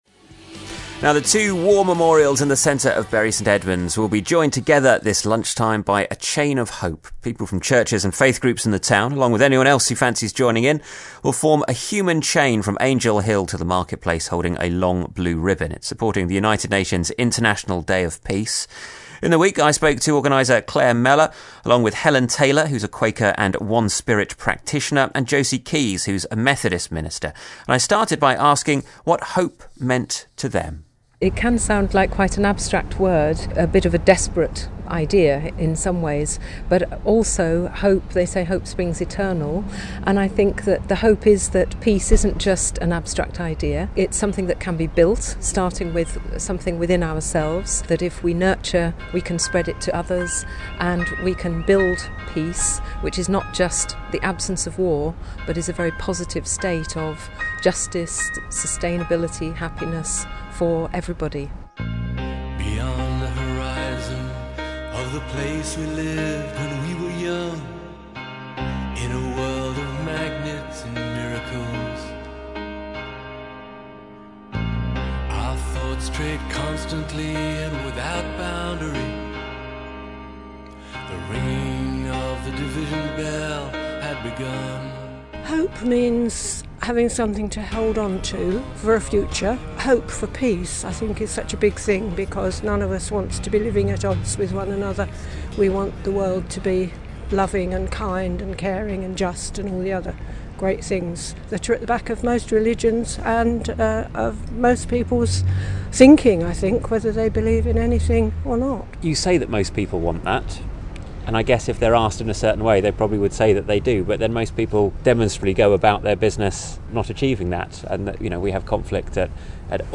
BBC Radio Suffolk interviewed